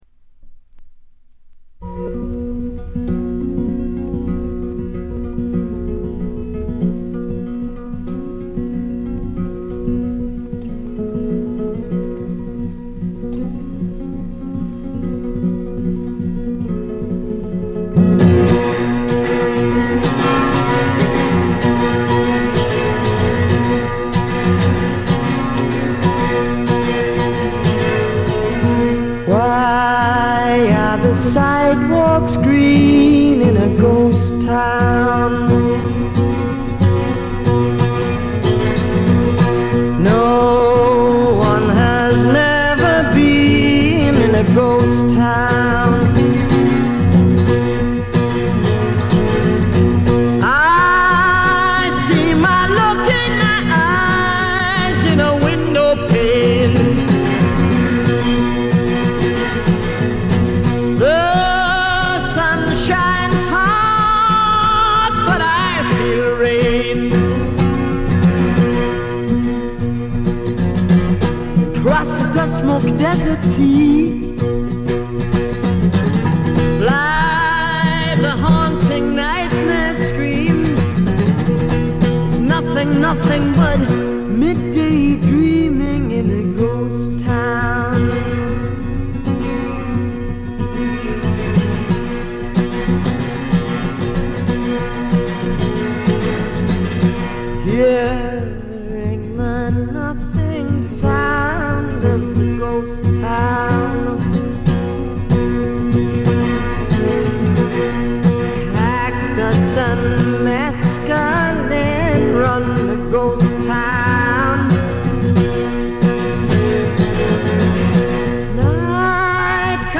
わずかなノイズ感のみ。
内容的にはおそろしく生々しいむき出しの歌が聞こえてきて、アシッド・フォーク指数が異常に高いです。
試聴曲は現品からの取り込み音源です。
Vocals, Acoustic Guitar